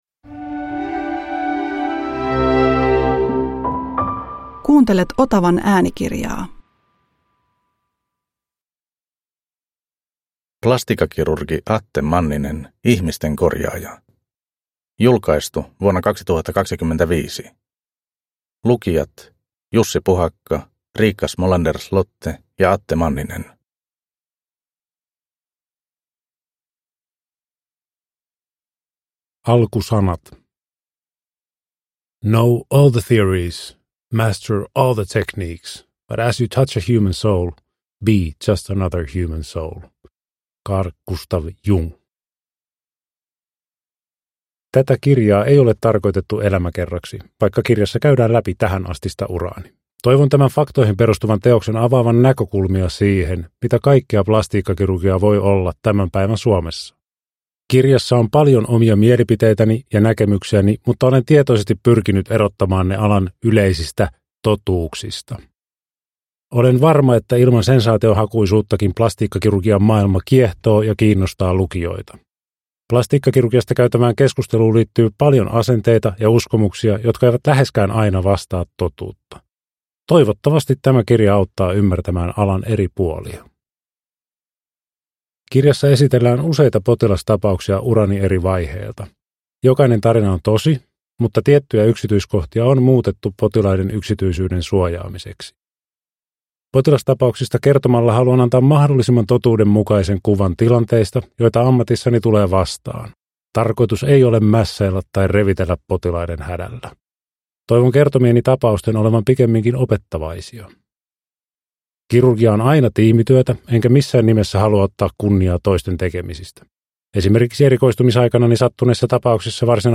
Plastiikkakirurgi (ljudbok) av Riikka Smolander-Slotte